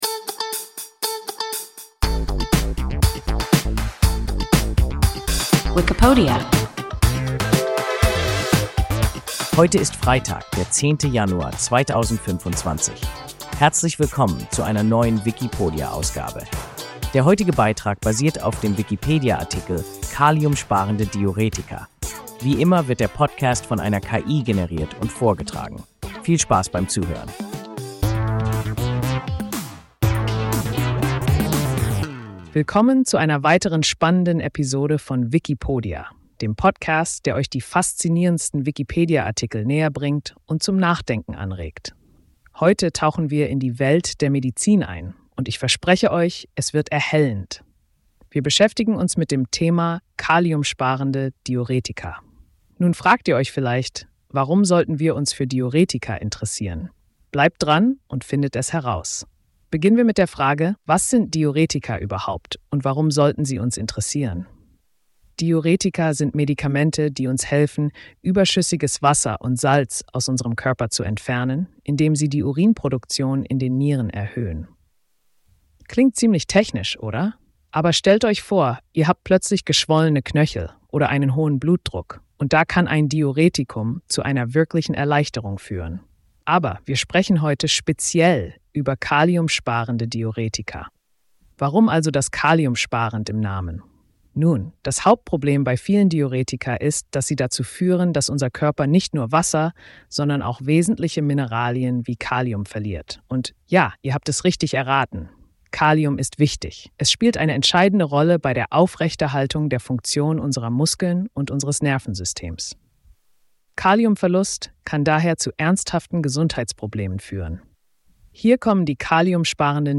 Kaliumsparende Diuretika – WIKIPODIA – ein KI Podcast